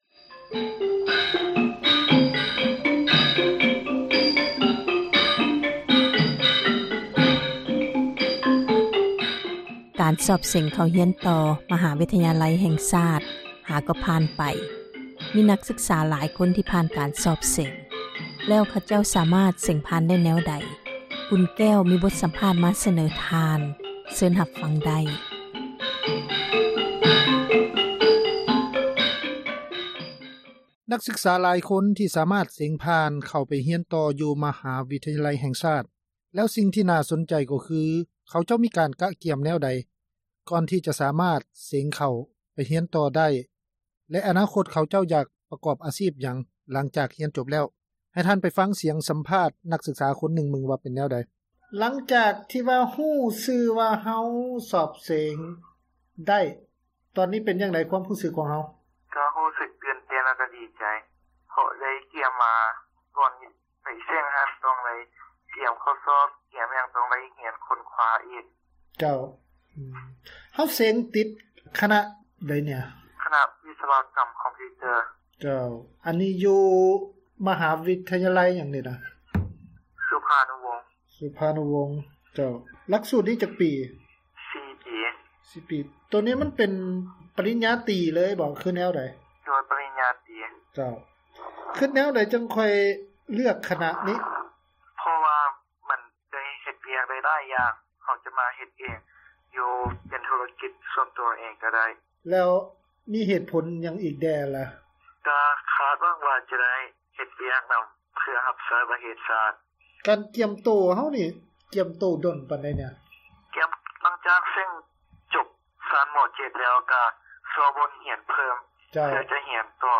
ສໍາພາດນັກສຶກສາລາວ ສອບເສັງ ຮຽນຕໍ່ ຂັ້ນສູງ